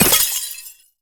ice_spell_impact_shatter_10.wav